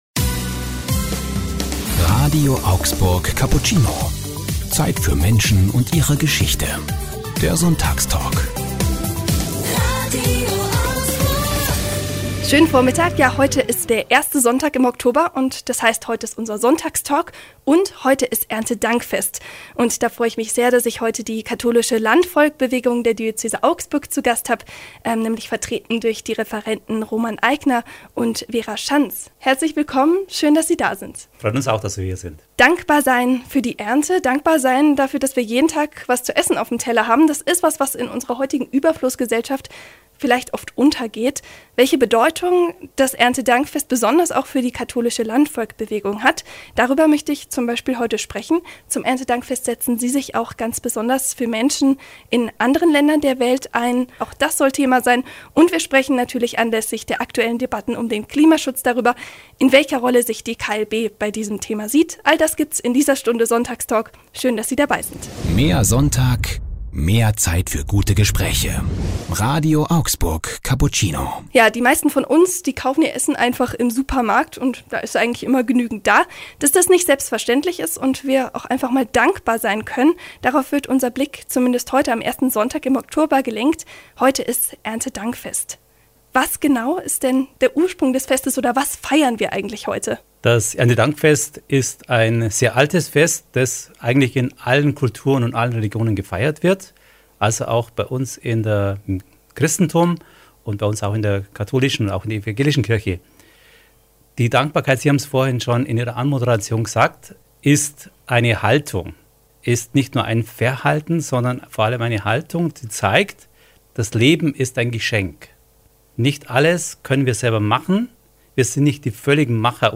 Erntedankfest: Sonntagstalk mit der Katholischen Landvolkbewegung ~ RADIO AUGSBURG Cappuccino Podcast